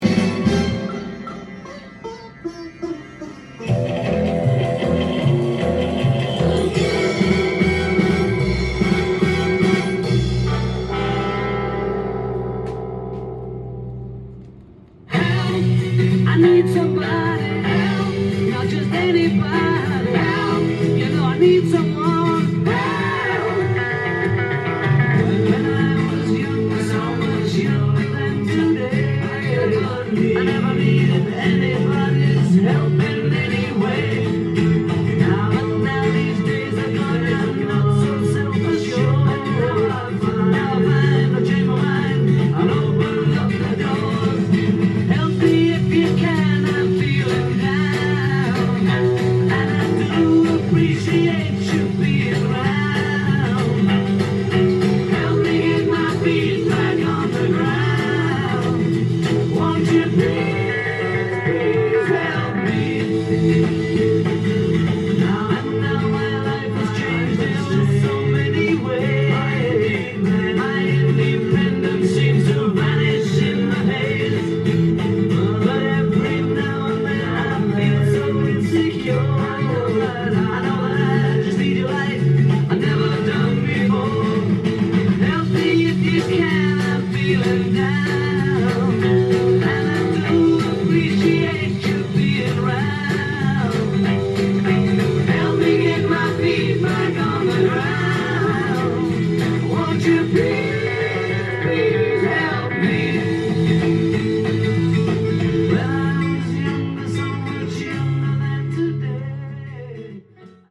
ジャンル：ROCK & POPS
店頭で録音した音源の為、多少の外部音や音質の悪さはございますが、サンプルとしてご視聴ください。
音が稀にチリ・プツ出る程度